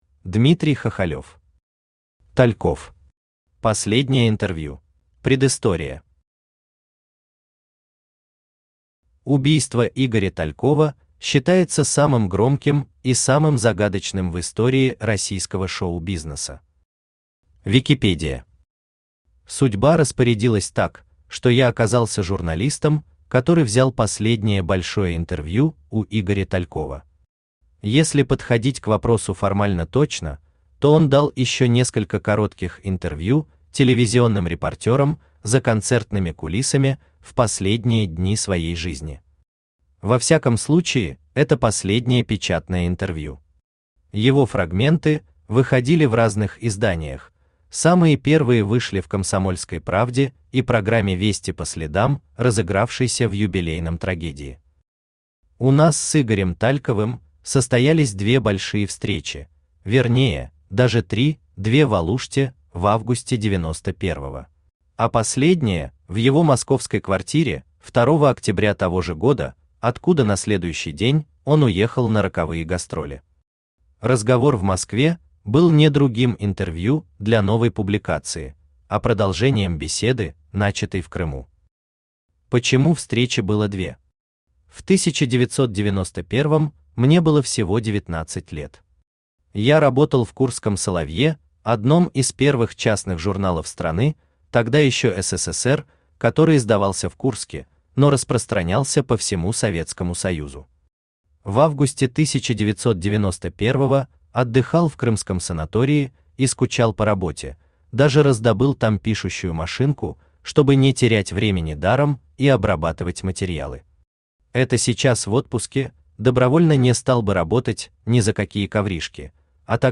Аудиокнига Тальков. Последнее интервью | Библиотека аудиокниг
Последнее интервью Автор Дмитрий Хахалев Читает аудиокнигу Авточтец ЛитРес.